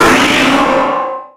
Cri de Méga-Ectoplasma dans Pokémon X et Y.
Cri_0094_Méga_XY.ogg